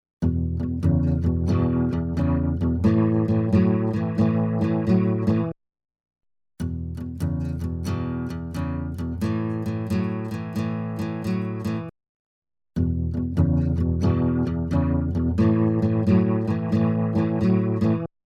渦巻くクラシック・ロータリーサウンド
Rotary Mod | Acoustic Guitar | Preset: Whirling Dervish
Rotary-Eventide-Acoustic-Guitar-Whirling-Dervish.mp3